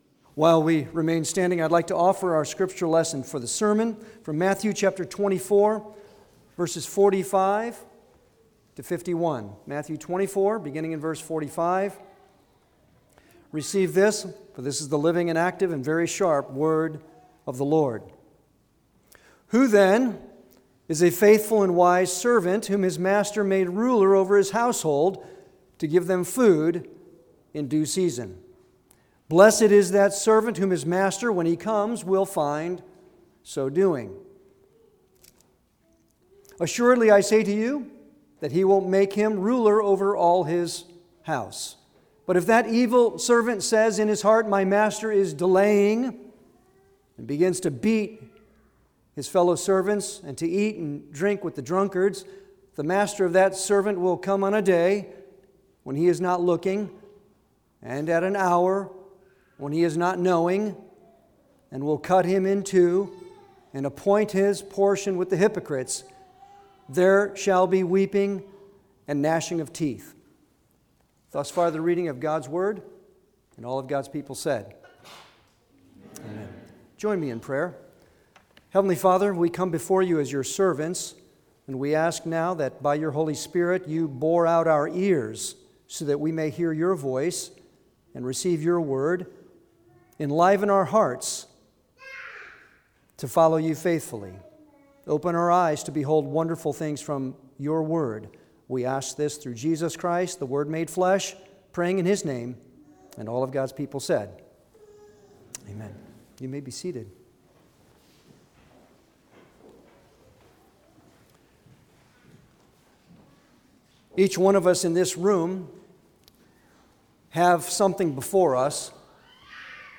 Series: Sermons on the Gospel of Matthew Passage: Matthew 24:45-51 Service Type: Sunday worship